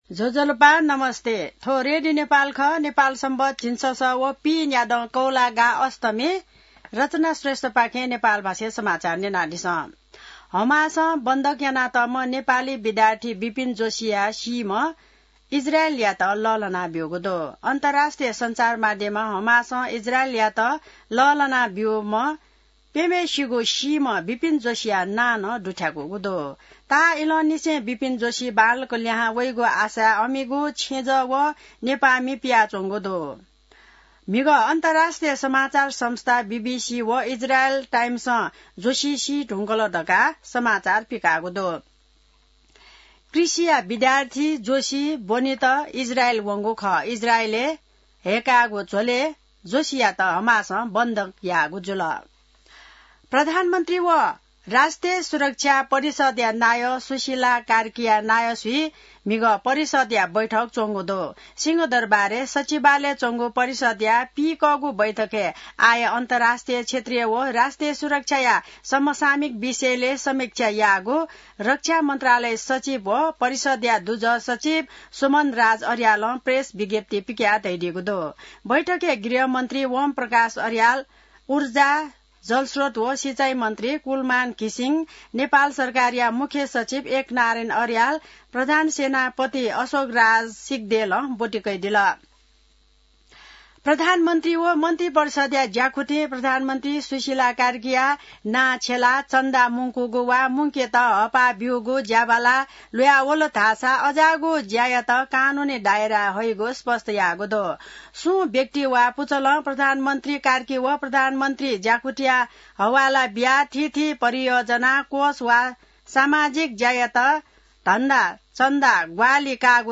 नेपाल भाषामा समाचार : २८ असोज , २०८२